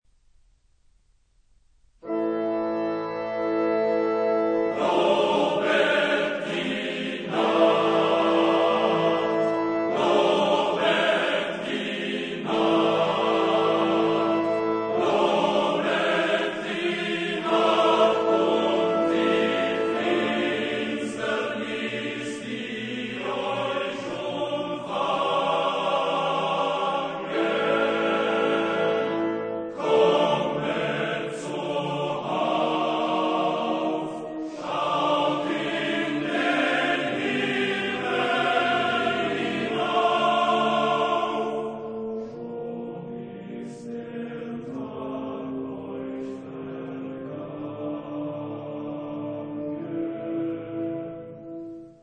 Genre-Style-Forme : Profane ; Chanson politique
Type de choeur : TTBB  (4 voix égales d'hommes )
Tonalité : do mineur